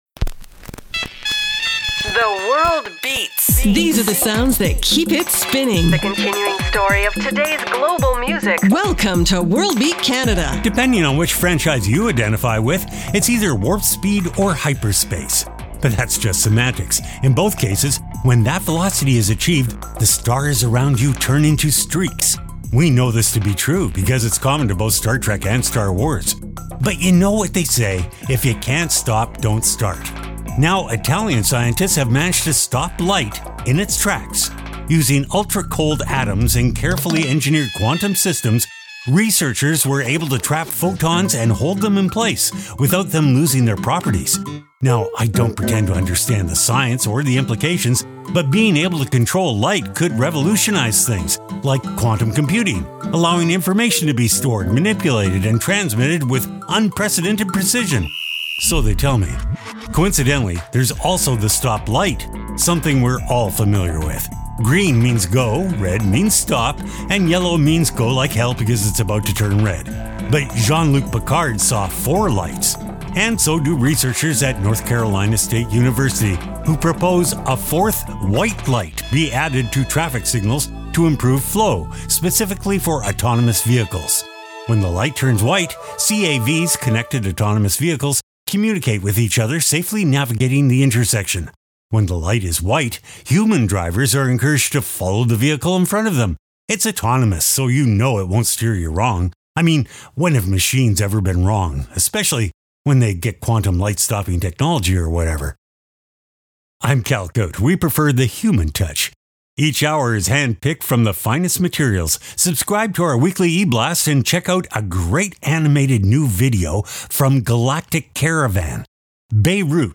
exciting global music alternative to jukebox radio Program Type: Weekly Program